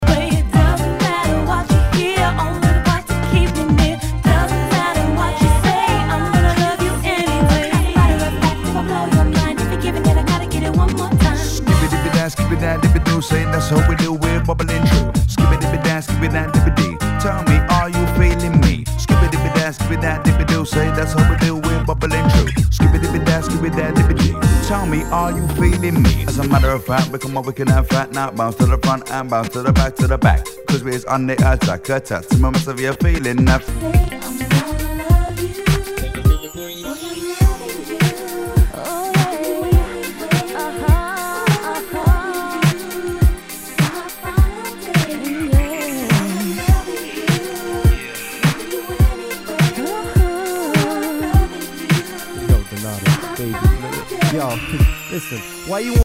HIPHOP/R&B
UK Garage / R&B！